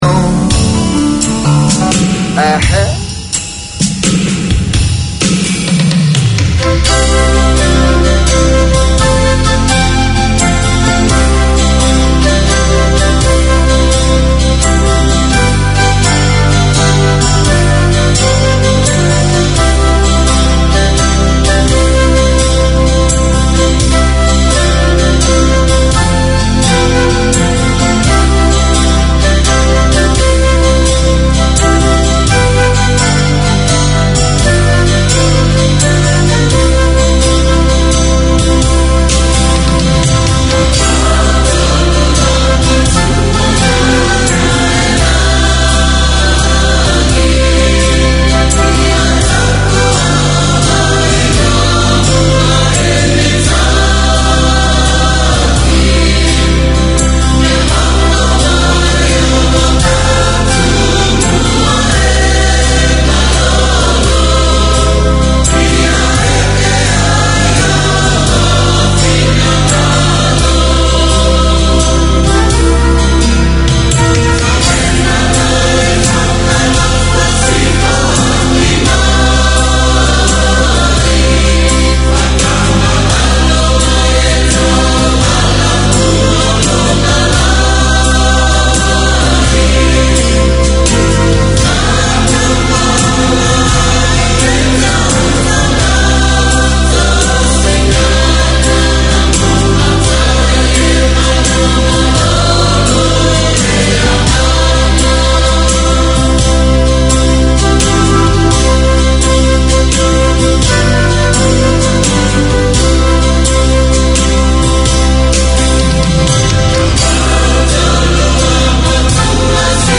Community Access Radio in your language - available for download five minutes after broadcast.